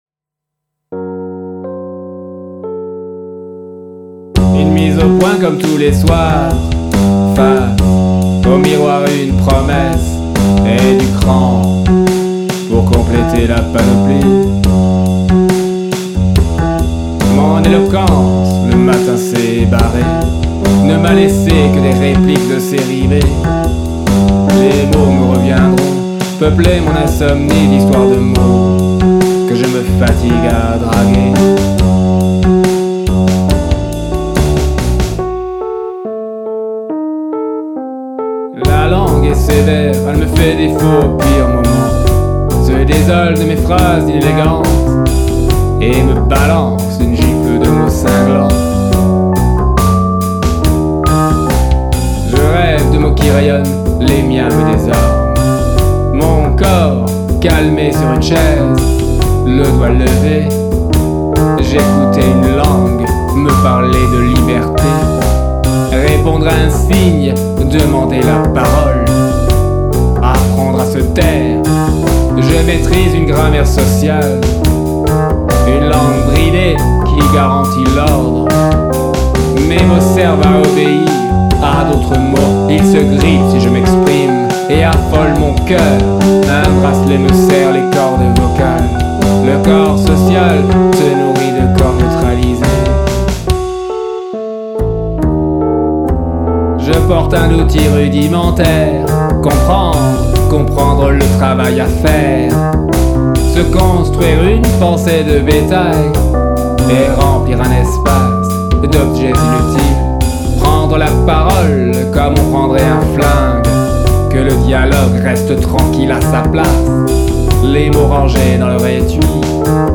se taire texte et musique